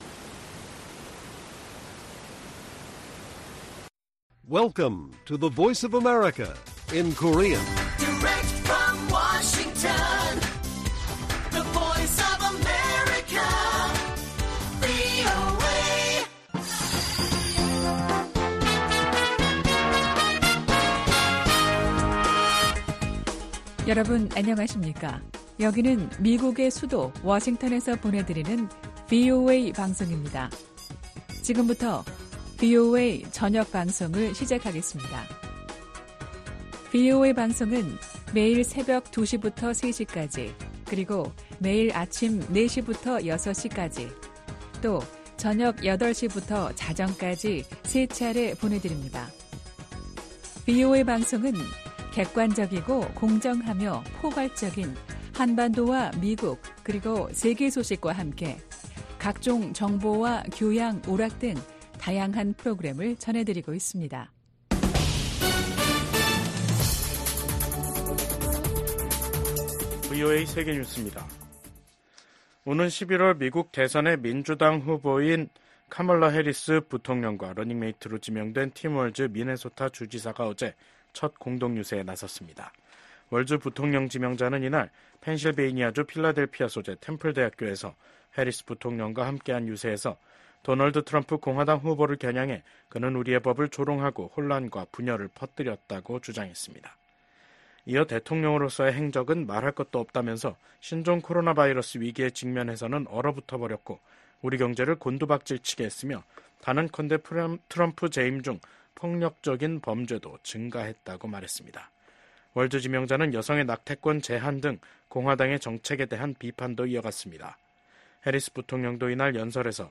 VOA 한국어 간판 뉴스 프로그램 '뉴스 투데이', 2024년 8월 7일 1부 방송입니다. 오는 11월 미국 대선에서 민주당 후보로 나설 예정인 카멀라 해리스 부통령이 팀 월즈 미네소타 주지사를 부통령 후보로 지명했습니다. 미국과 호주의 외교∙국방장관들이 북한과 러시아의 군사 협력을 규탄했습니다.